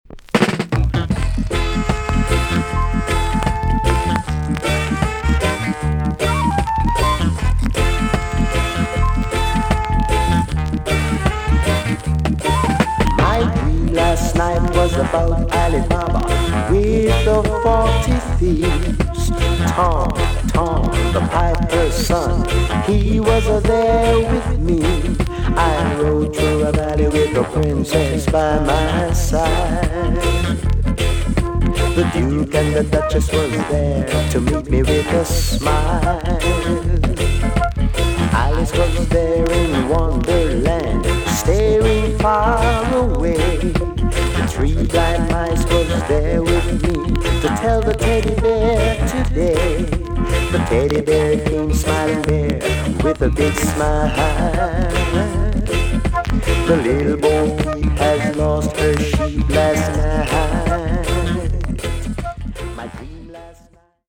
TOP >SKA & ROCKSTEADY
VG+ 少し軽いチリノイズが入ります。